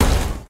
fail.ogg